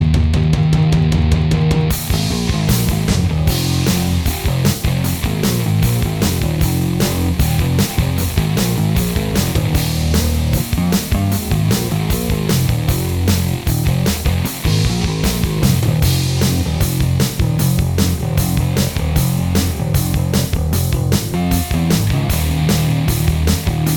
Minus Lead Rock 5:37 Buy £1.50